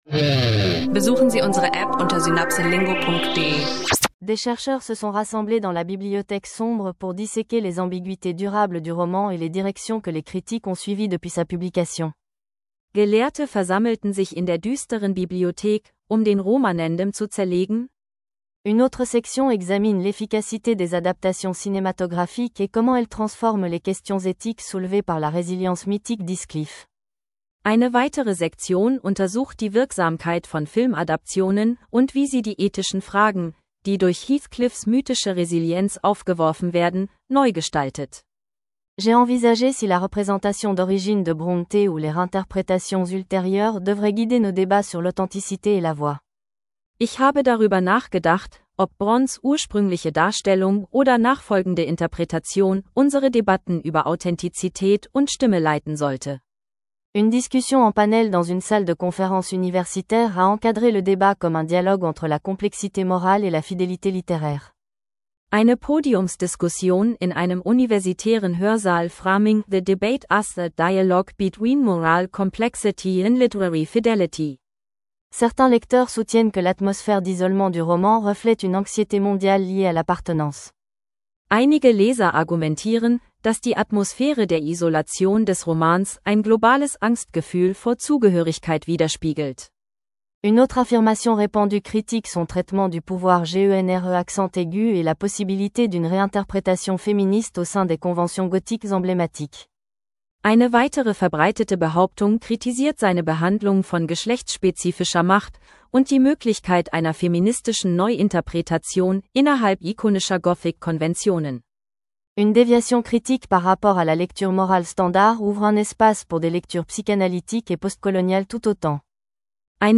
SynapseLingo Französisch lernen Podcast – Debatte zu Wuthering Heights im Französisch/Deutsch Dialog